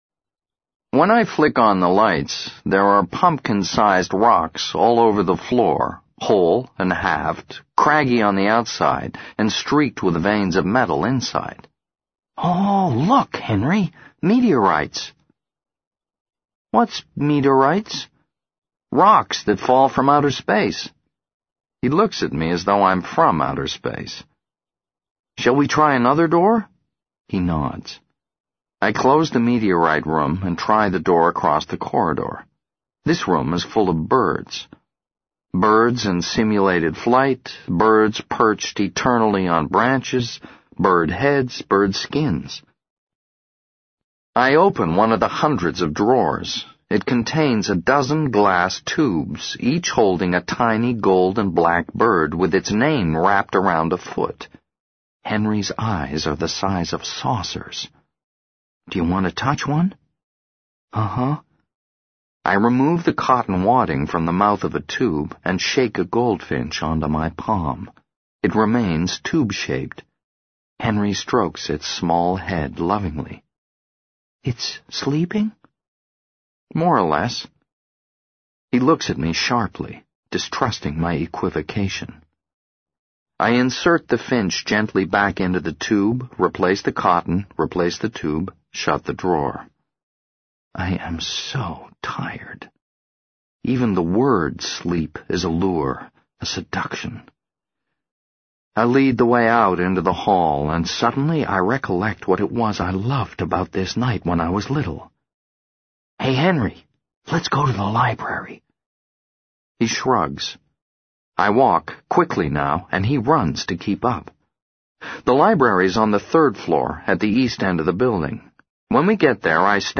在线英语听力室【时间旅行者的妻子】28的听力文件下载,时间旅行者的妻子—双语有声读物—英语听力—听力教程—在线英语听力室